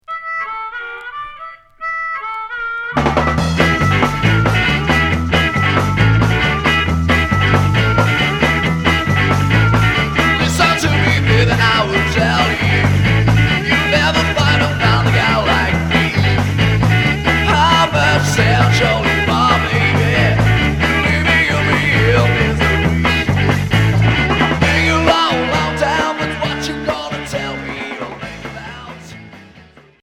Garage